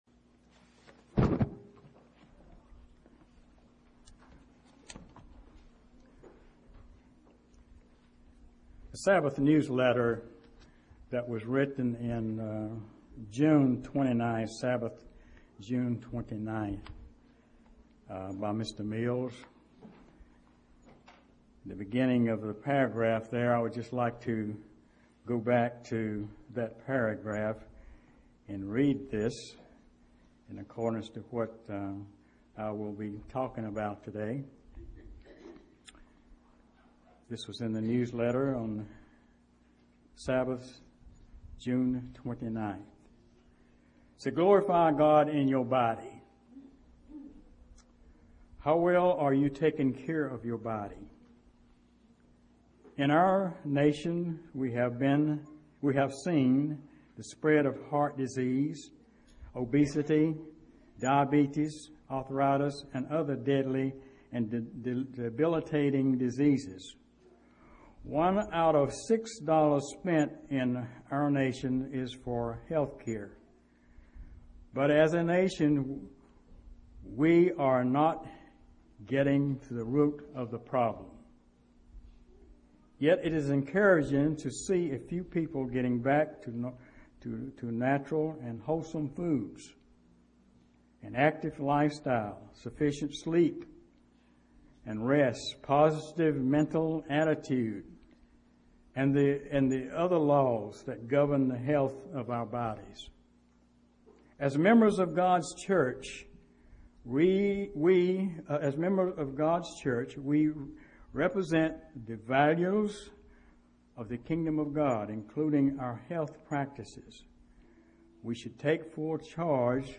UCG Sermon Studying the bible?
Given in Greensboro, NC